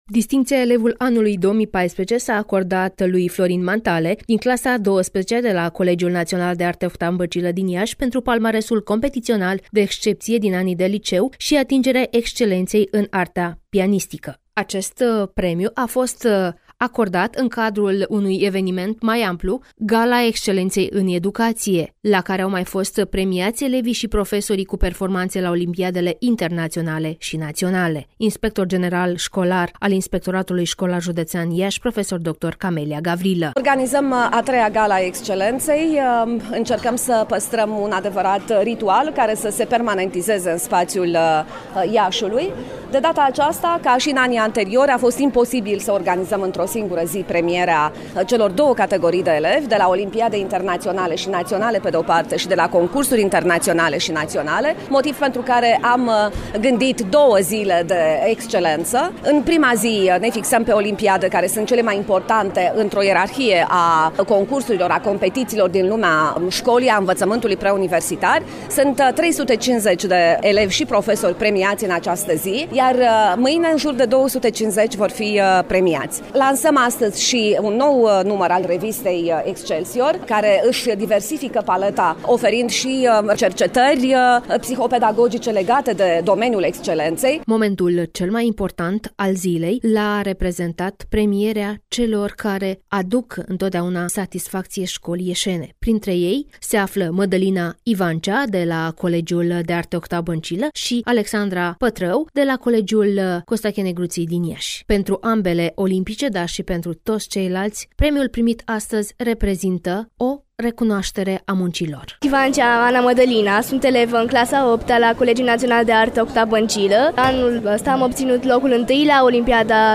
(REPORTAJ) Gala Excelenţei în educaţie la Iaşi